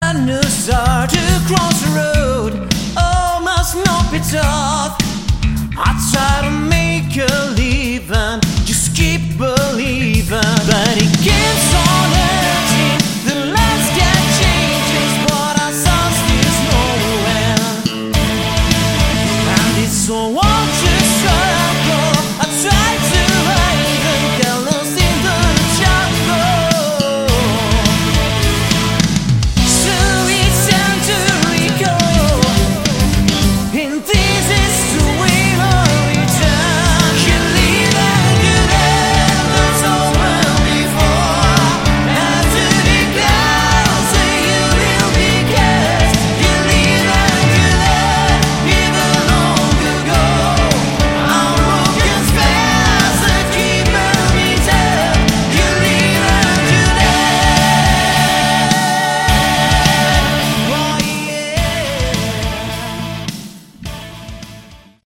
Category: AOR / Melodic Rock
lead, backing vocals
guitar, backing vocals
bass, backing vocals
keyboards, backing vocals
drums, percussion